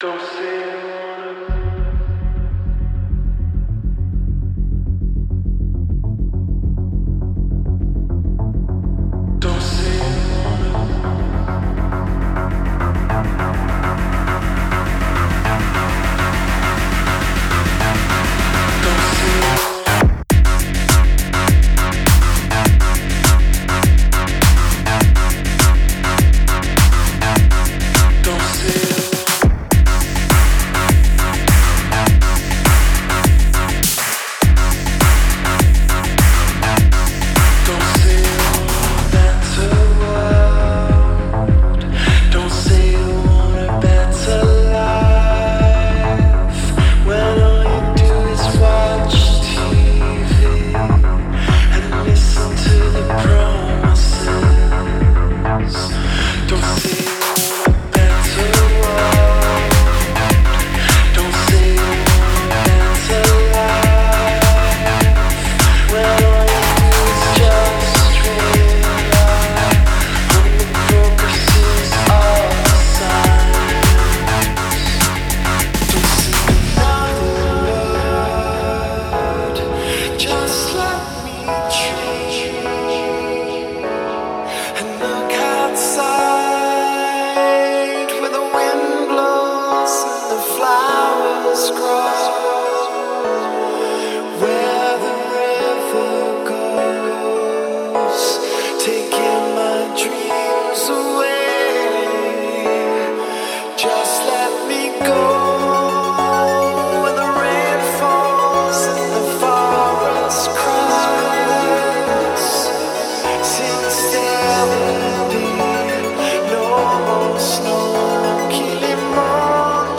Genre: New Age